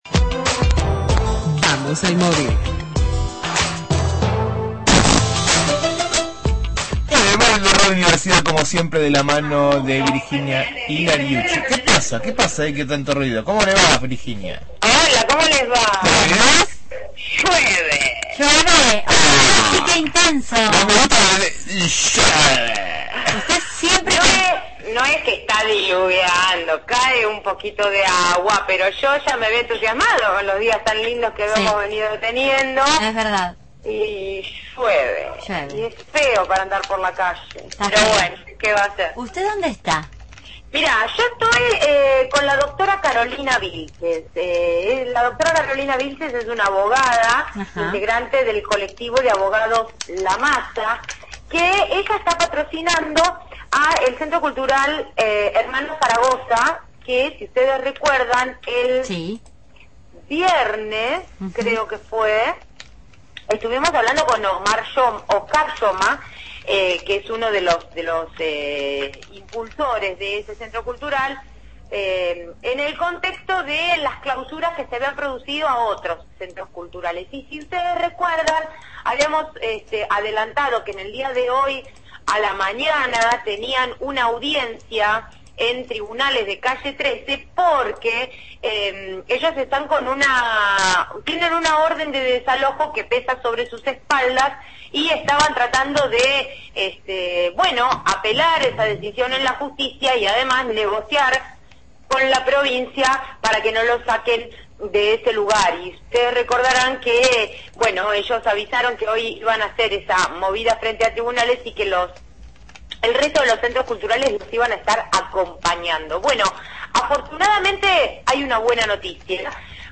Móvil/ Frenan desalojo del Centro Cultural Hermanos Zaragoza – Radio Universidad